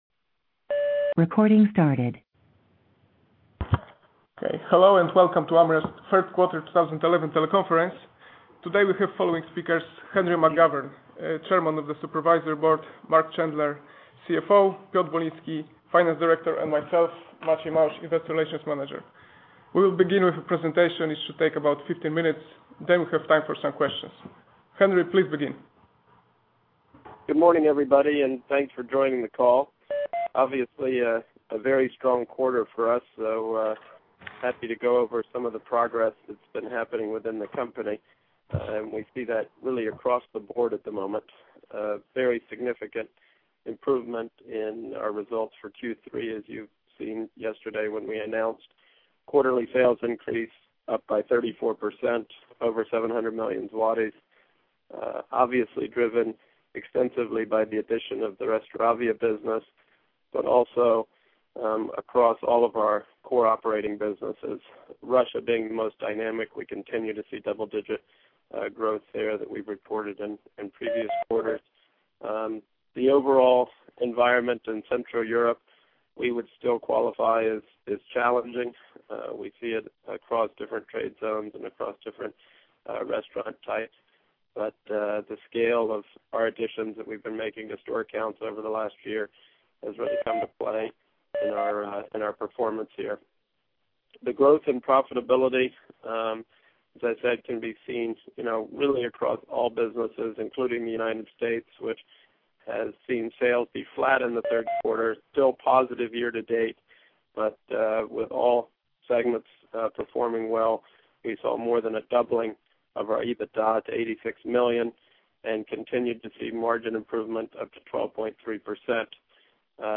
Telekonferencja inwestorska 3Q 2011, 15.11.2011